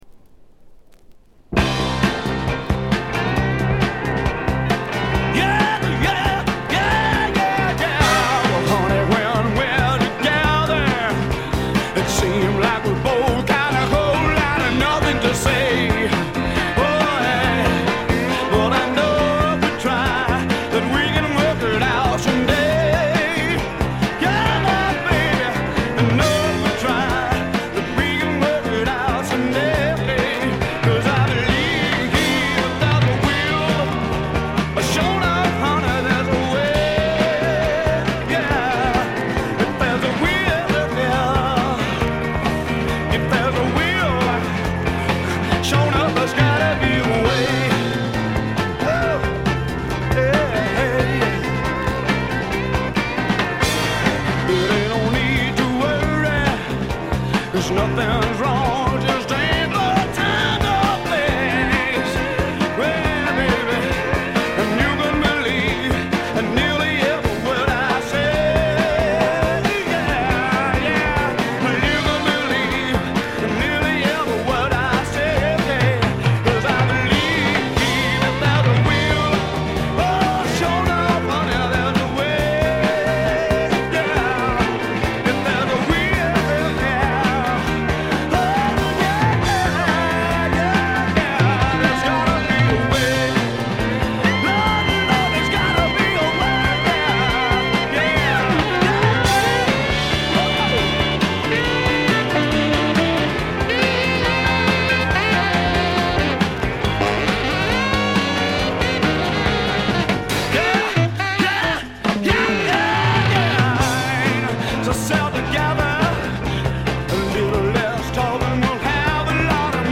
部分試聴ですがチリプチ少々程度。
スワンプ・ロックの基本定番！！！
試聴曲は現品からの取り込み音源です。
January 1971 Olympic Sound Studios, Barnes, London